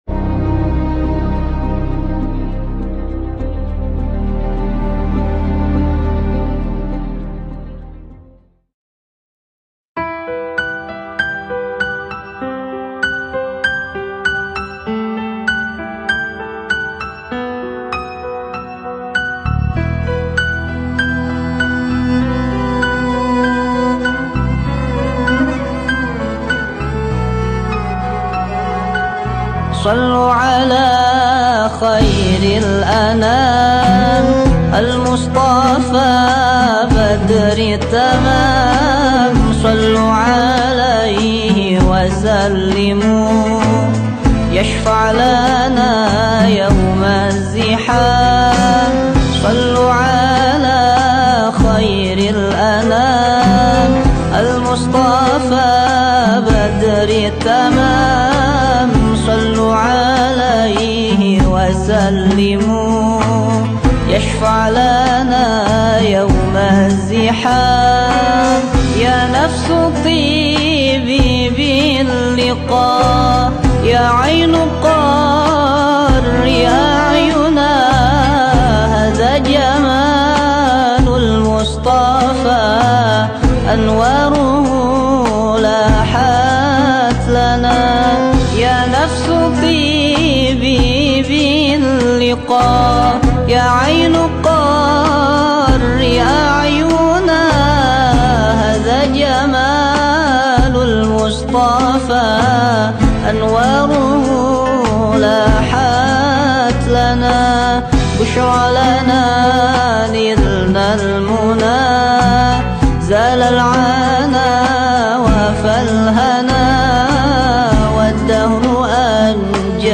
Indonesian Sufi Music